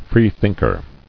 [free·think·er]